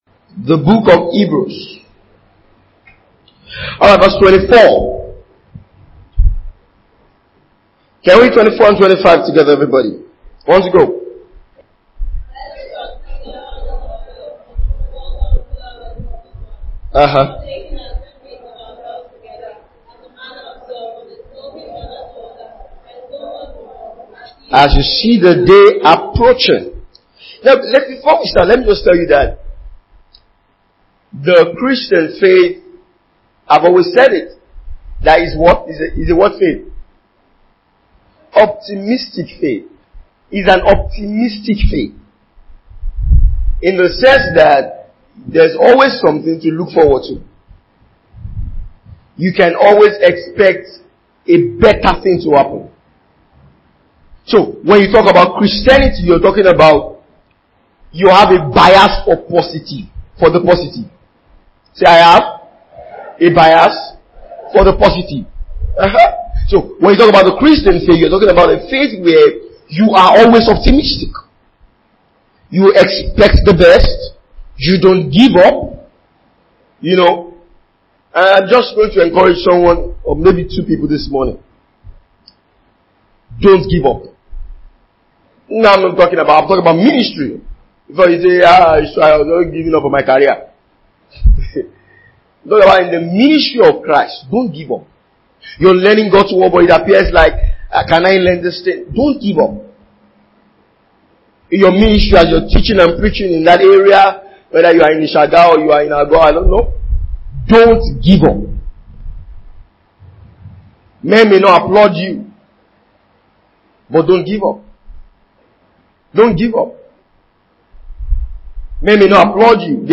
Glorious House Church Teachings.